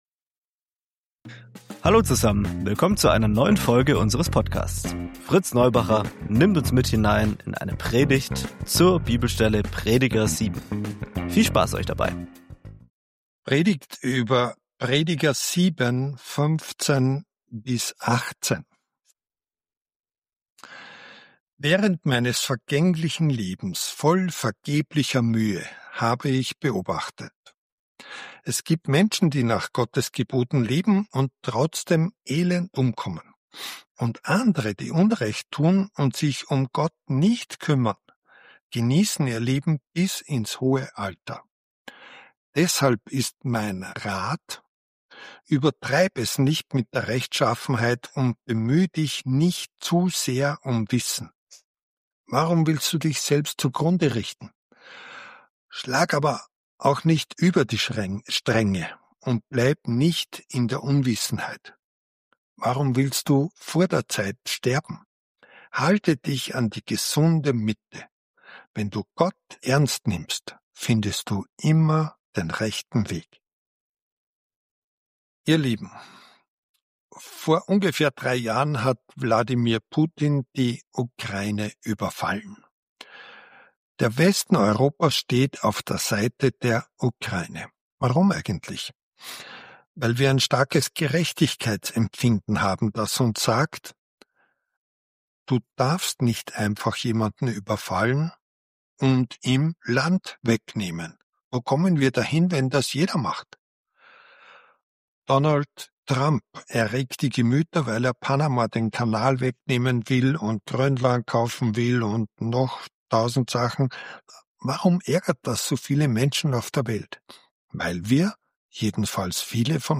Das Leben ist brüchig, ungerecht und oft schwer auszuhalten. Gott wird hier nicht als schnelle Lösung zum Gelingen des Lebens gepredigt, sondern als Maßstab und Orientierung mitten in der Vergänglichkeit. Wer in Ehrfurcht vor Gott lebt, findet Halt – auch ohne einfache Antworten.